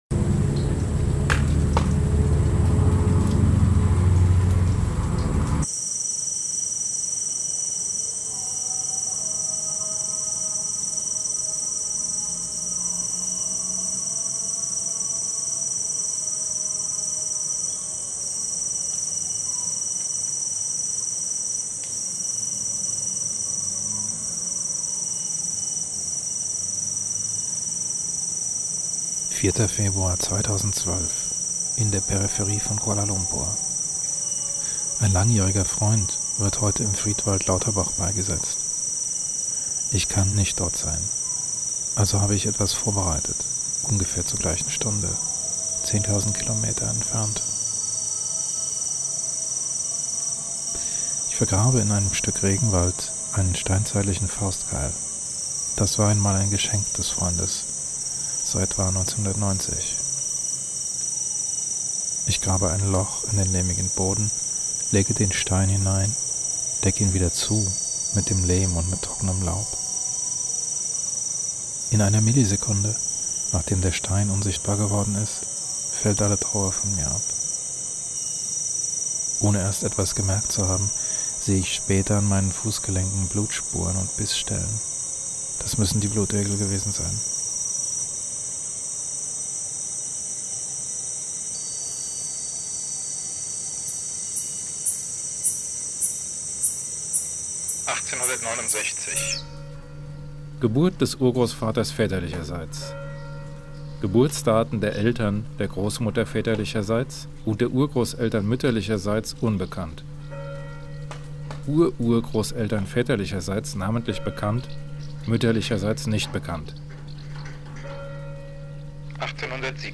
Experimentelles Radio